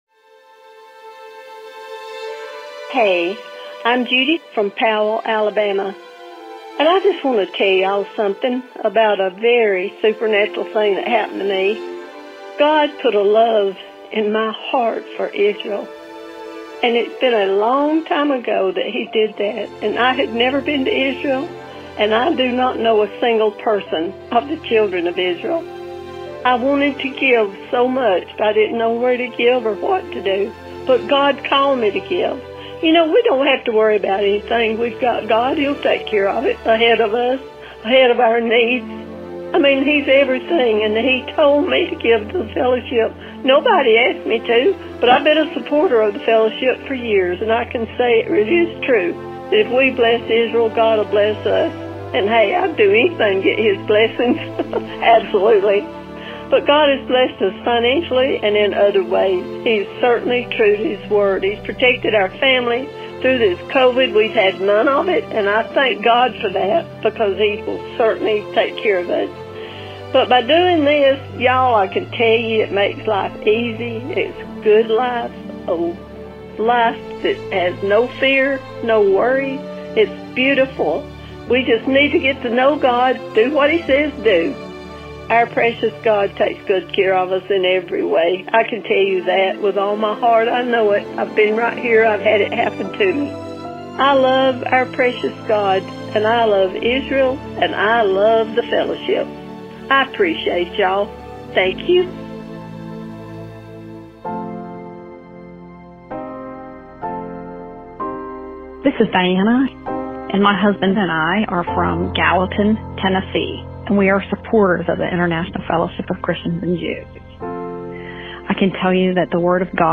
To help inspire you, LISTEN to the audio below of testimonials from four of our precious Fellowship friends and supporters.
Why-Christians-Bless-Israel-4-IFCJ-Donor-Testimonials.mp3